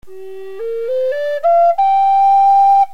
、ウォルナット（胡桃。固く重い。艶々として明るく甘い音。）
キイ(管の長さ)、材質別の音のサンプルです。
Gm管 ウォルナット製
ネイティブアメリカン インディアン フルート 音